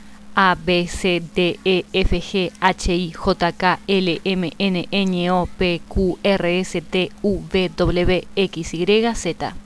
I have pronounced each letter for you.
alphabet.wav